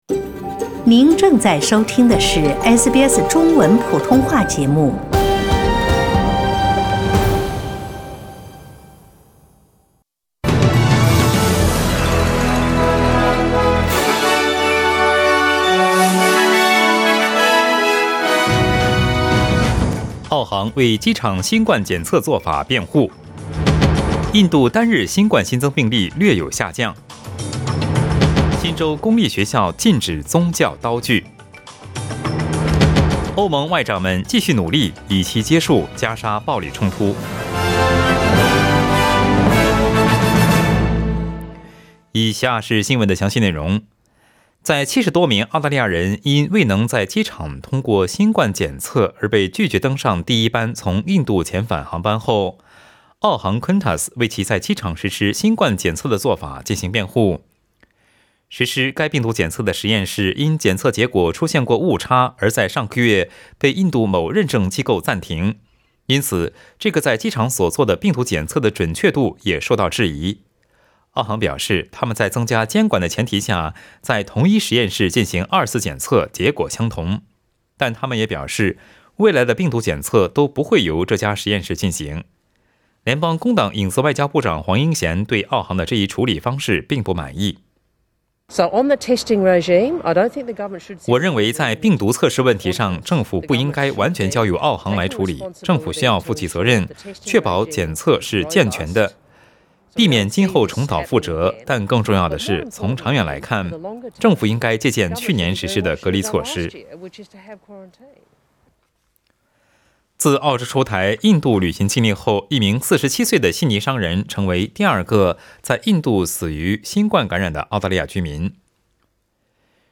SBS早新聞 （5月19日）
SBS Mandarin morning news Source: Getty Images